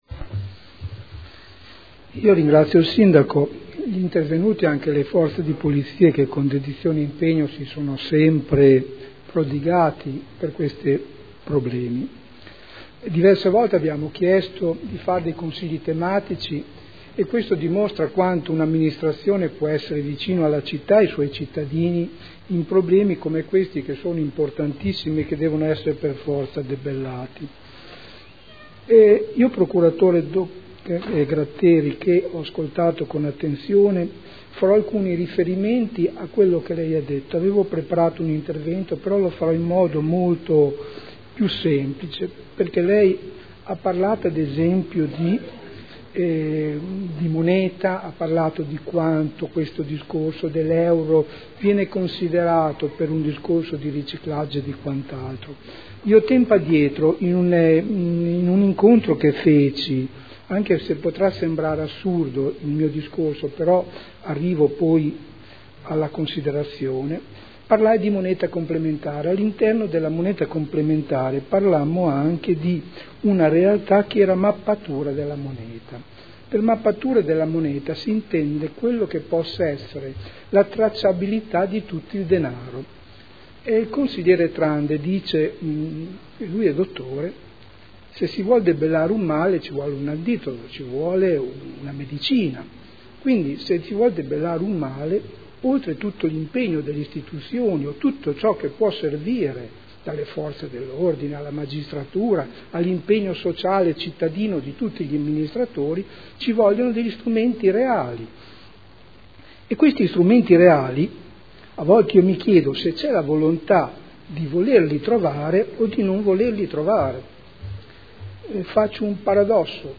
Seduta del 25/03/2013. Dibattito su Ordine del Giorno Unitario su impegno del Comune di costituirsi parte civile nelle politiche di prevenzione e contrasto alle mafie